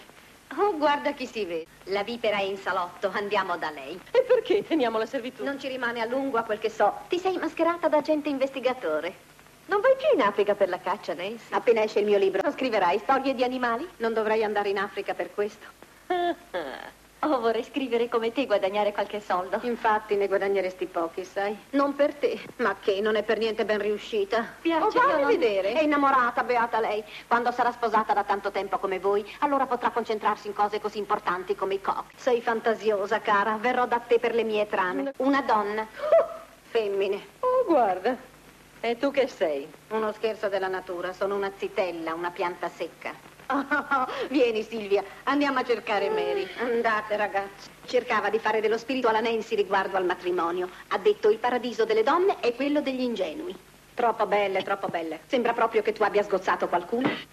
"Donne", in cui doppia Florence Nash.